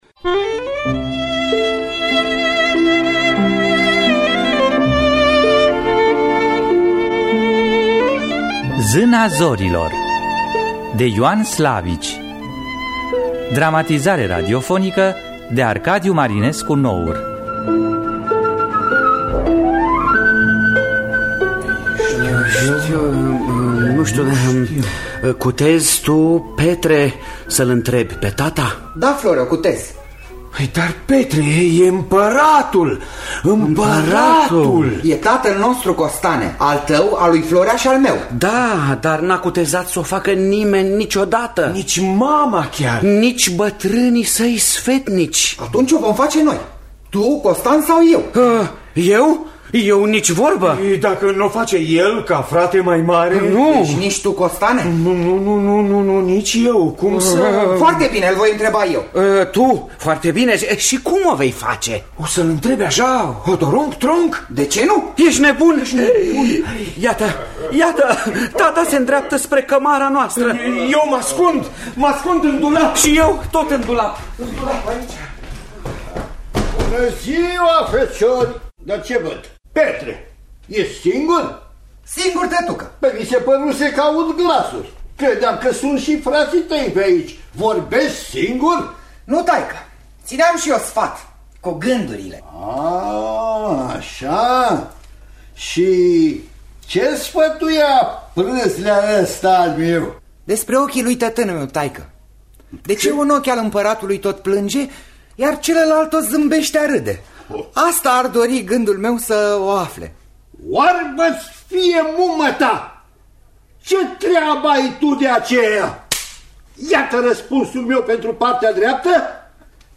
Zâna zorilor de Ioan Slavici – Teatru Radiofonic Online